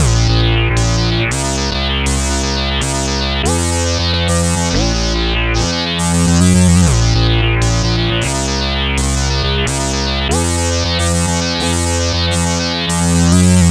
Exodus - Long Octave Synth.wav